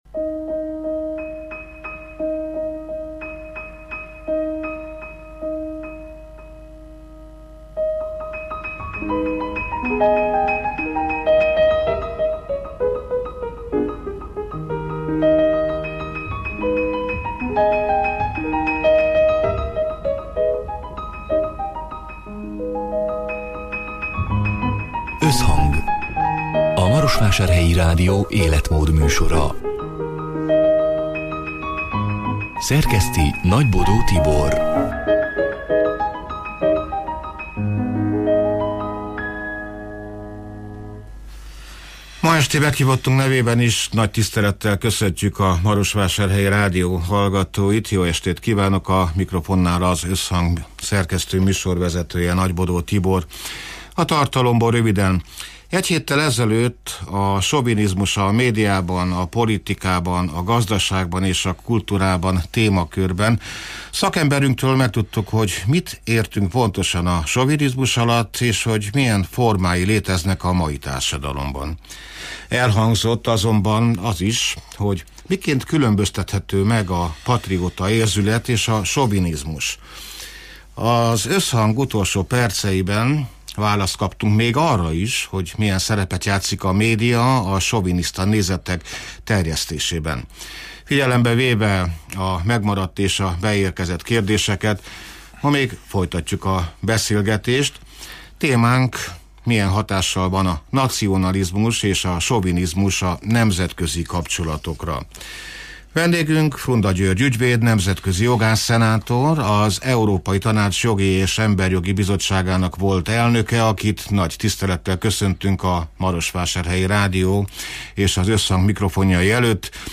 (elhangzott: 2025. január 29-én, szerdán délután hat órától élőben)